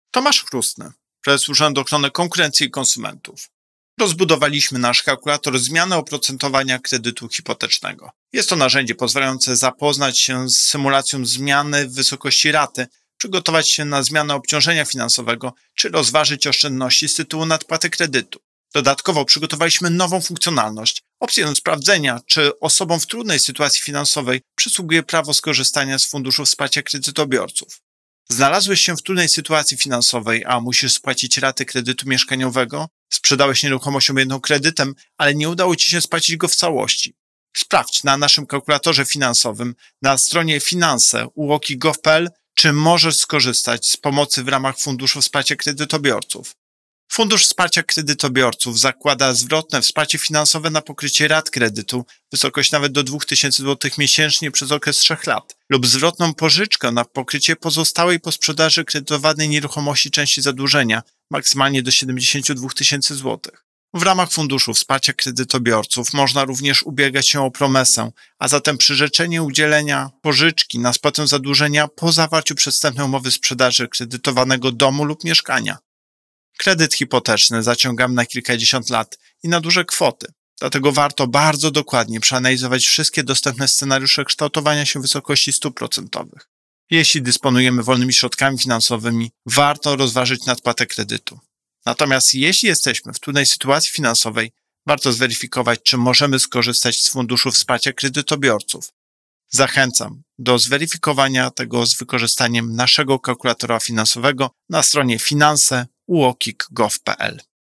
Dzięki kalkulatorowi finansowemu przygotowanemu przez UOKiK w prosty sposób wstępnie sprawdzisz, czy przysługuje Ci prawo do skorzystania z pomocy. Pobierz wypowiedź Prezesa UOKiK Tomasza Chróstnego